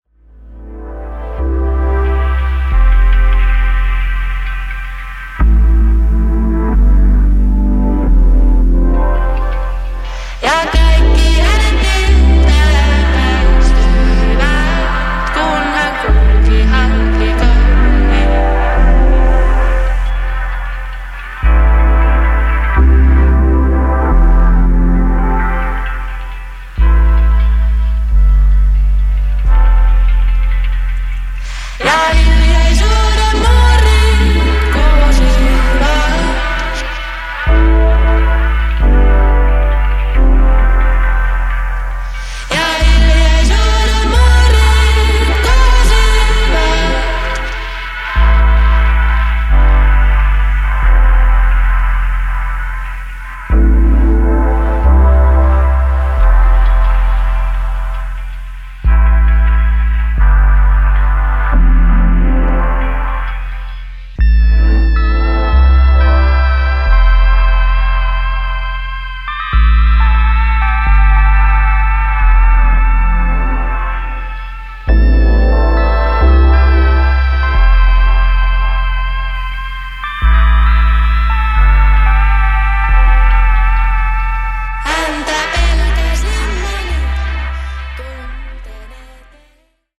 a hazy and mystical Ambient Dub version
Finnish singer
keys